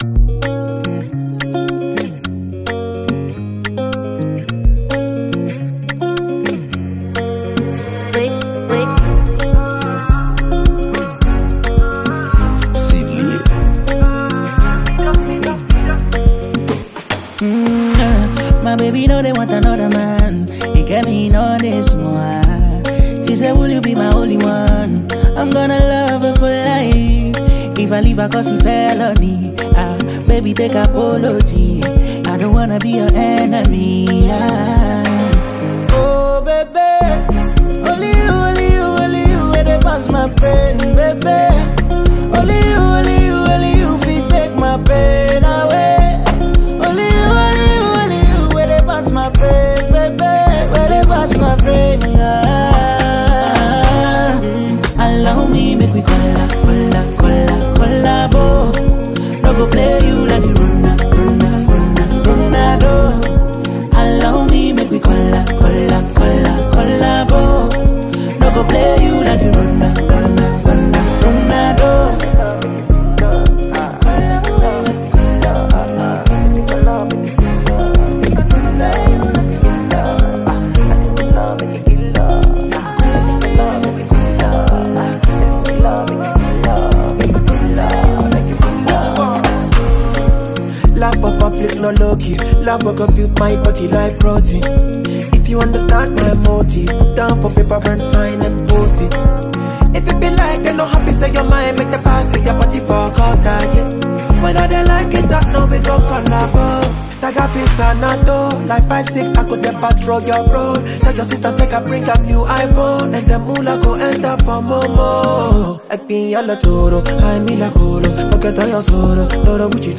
Ghanaian singer and song writer